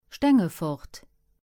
English: Pronunciation of the word "Stengefort" in Luxembourgish. Female voice.
Lëtzebuergesch: Aussprooch vum Wuert "Stengefort" op Lëtzebuergesch. Weiblech Stëmm.